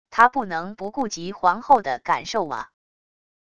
他不能不顾及皇后的感受啊wav音频生成系统WAV Audio Player